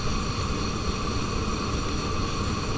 Gas Burn Loop 02.wav